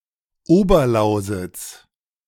Upper Lusatia (German: Oberlausitz, pronounced [ˈoːbɐˌlaʊzɪts]
De-Oberlausitz.ogg.mp3